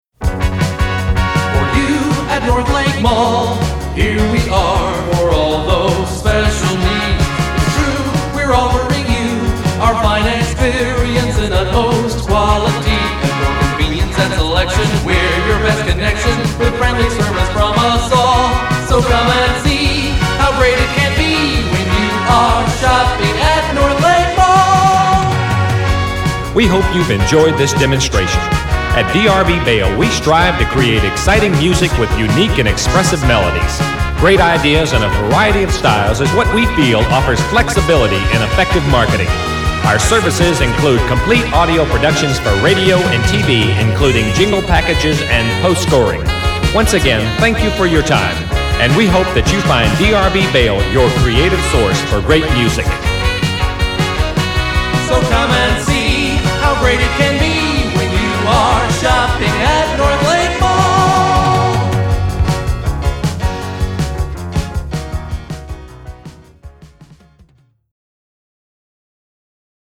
Jingles!